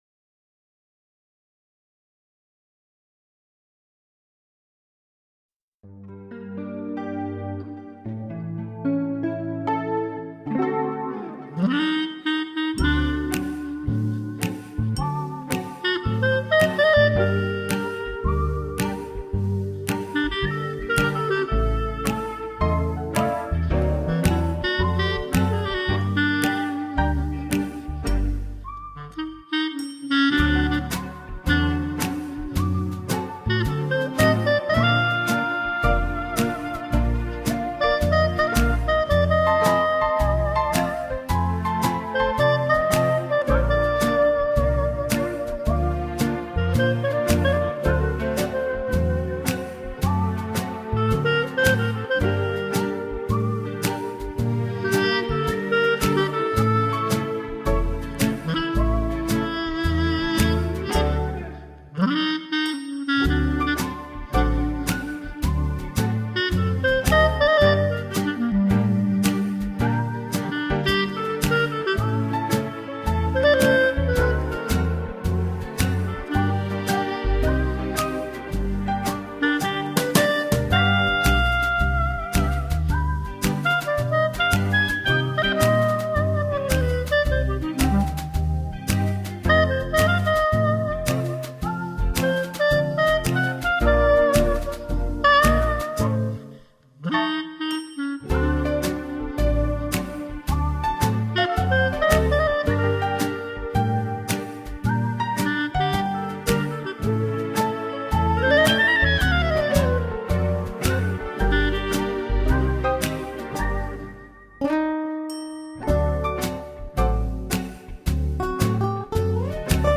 Clarinet
Guitar